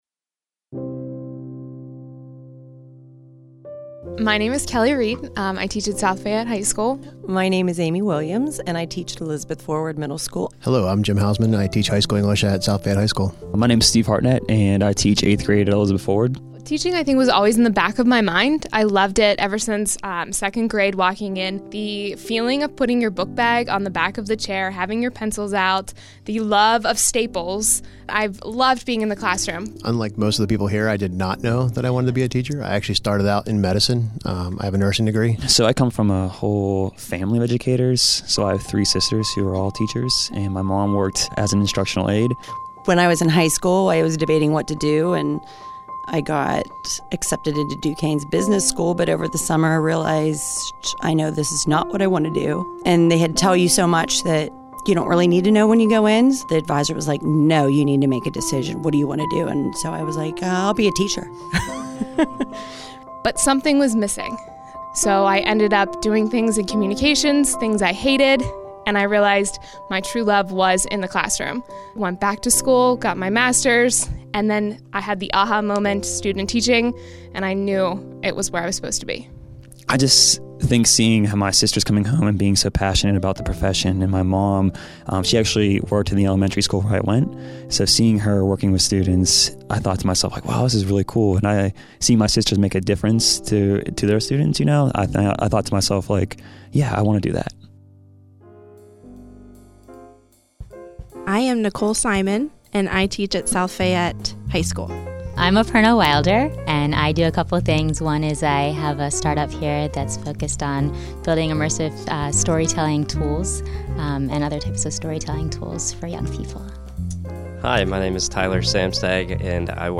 On February 21, 2018, SLB welcomed eleven teachers from multiple districts for a half-day workshop focused on creating and publishing radio and audio programs and classroom applications. This audio montage is culled from our icebreaker activity and features participants reflecting on their careers in teaching.